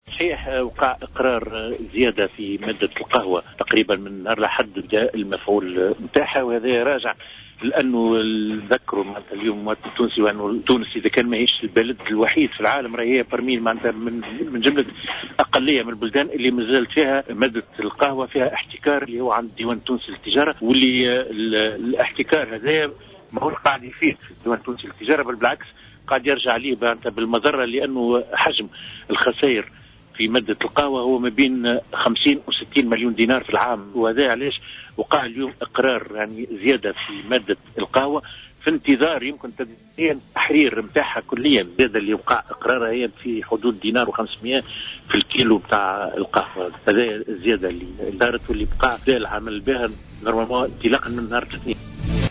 a confirmé dans une intervention sur les ondes de Jawhara FM ce mardi 6 août 2014